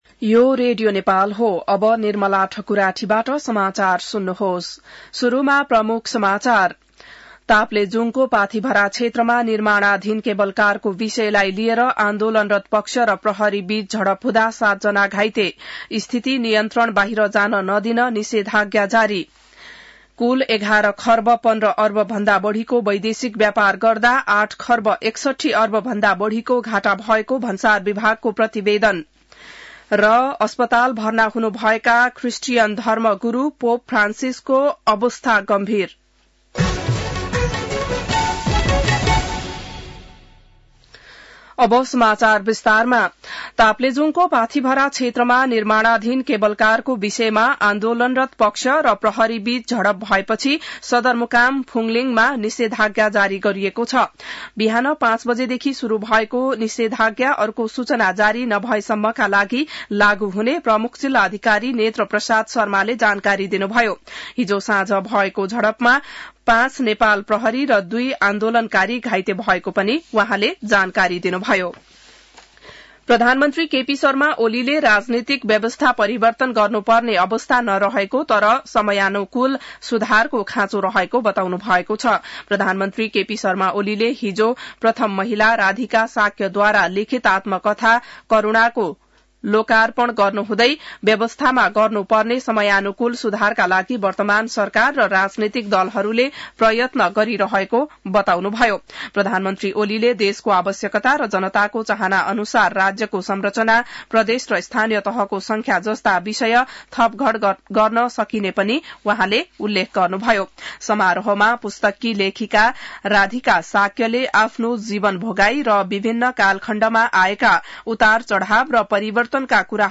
बिहान ९ बजेको नेपाली समाचार : १२ फागुन , २०८१